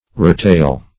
Search Result for " roughtail" : The Collaborative International Dictionary of English v.0.48: Roughtail \Rough"tail`\, n. (Zool.)